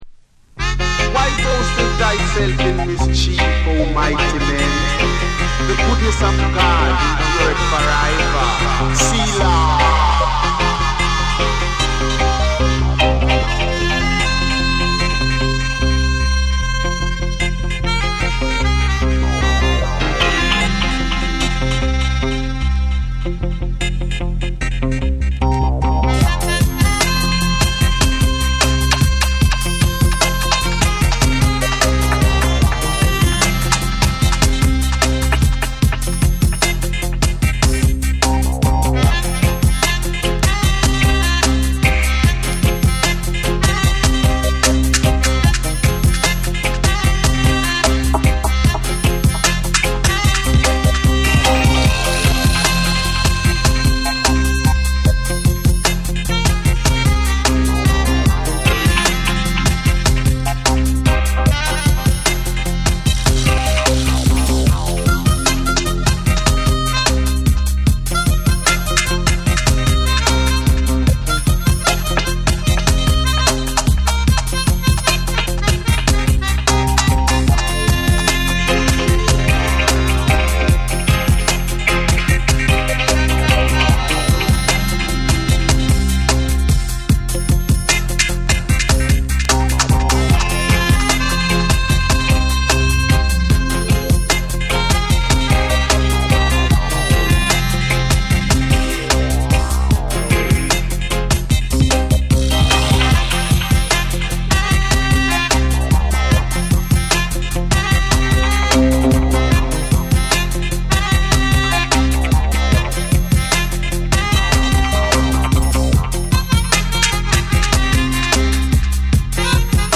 重厚なリディムに深く沈むベースと鋭いシンセが絡む
REGGAE & DUB